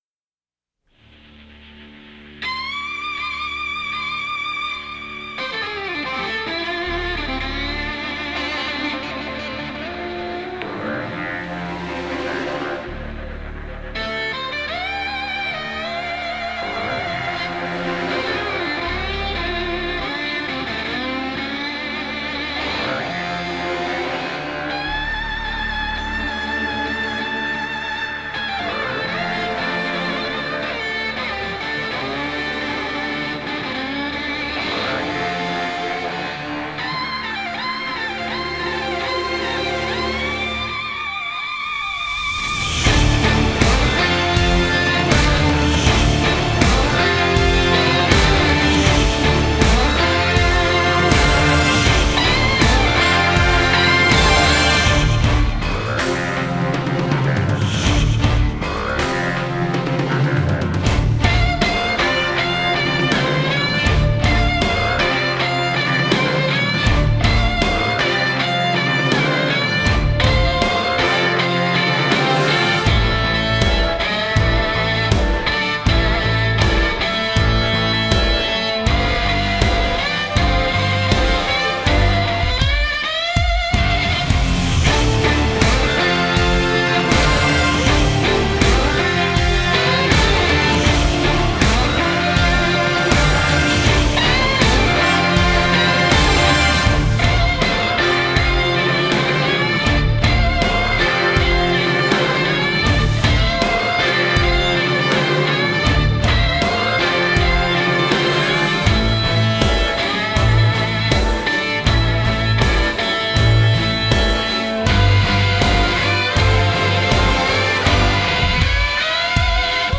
Genre: Score